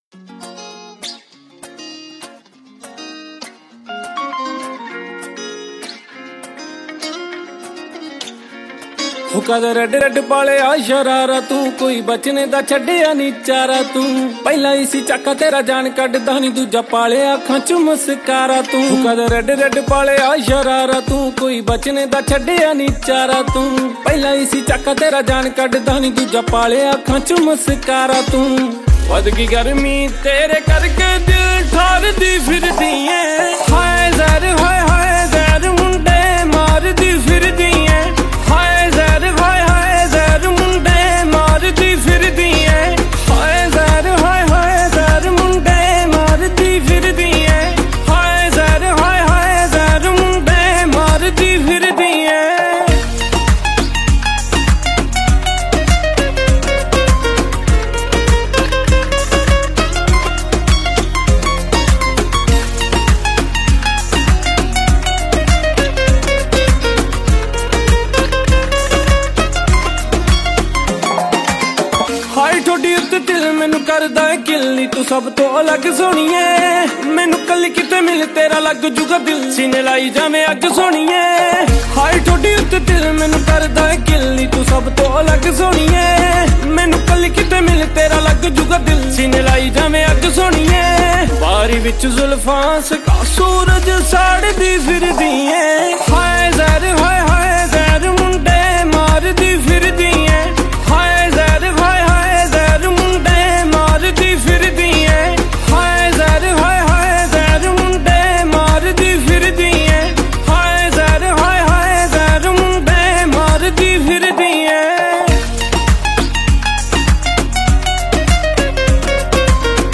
New Haryanvi Song 2023